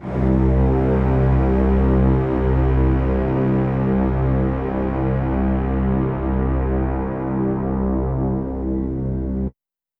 Drich Trumpets Brass Horns (190).wav